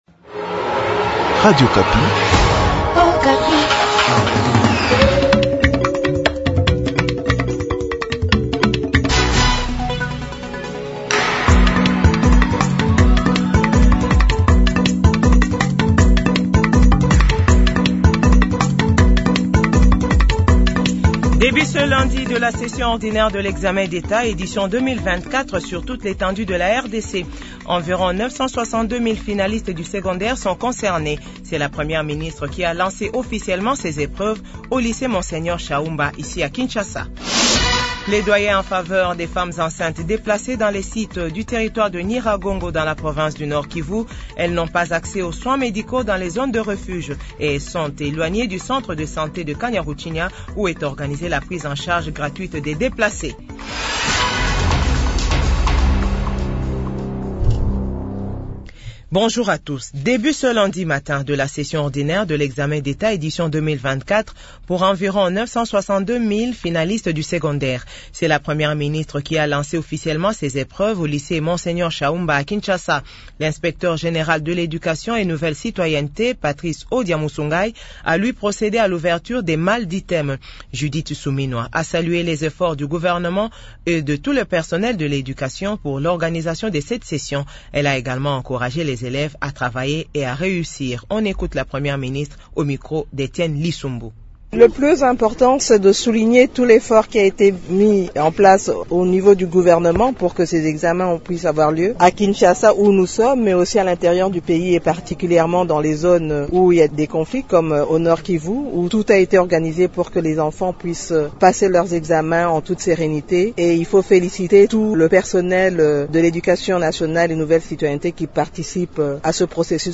JOURNAL FRANÇAIS 15H00